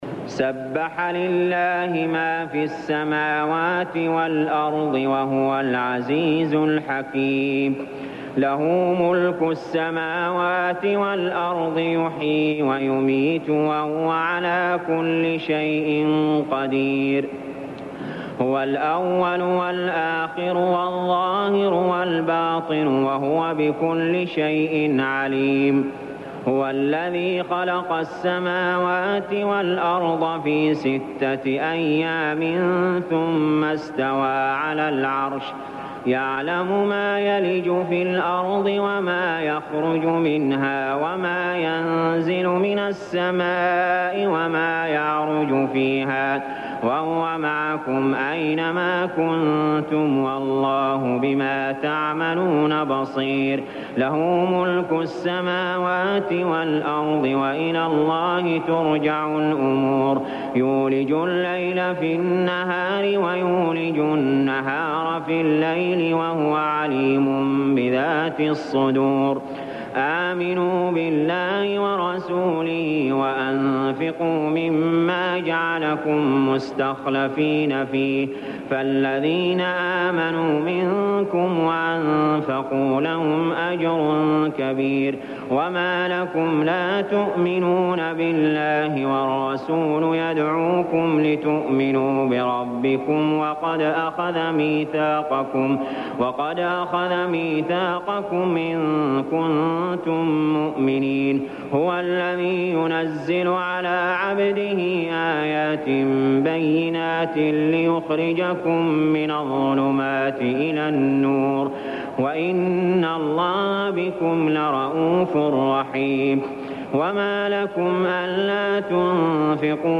المكان: المسجد الحرام الشيخ: علي جابر رحمه الله علي جابر رحمه الله الحديد